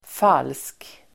Uttal: [fal:sk]